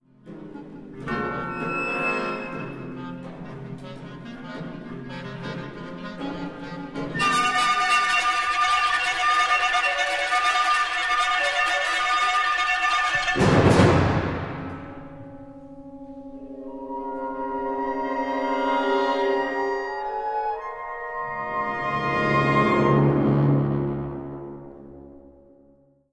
for wind orchestra
小編成ウィンド・オーケストラのための作品
Timpani
for wind orchestra sample